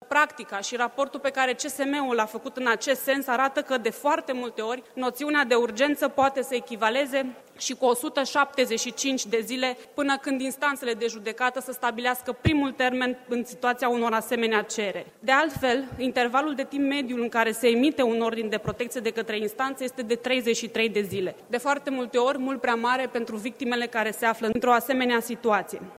Deputatul liberal Valeria Schelean spune că un astfel de act normativ era necesar, având în vedere că în România, la fiecare şase minute, la apelul de urgenţă 112 este raportată o acţiune de violenţă în familie: